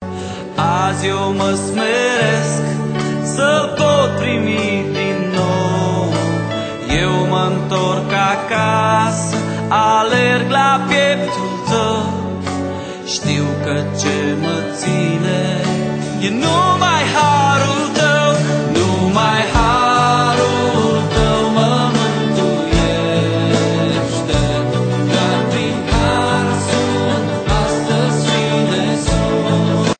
Album de lauda si inchinare inregistrat live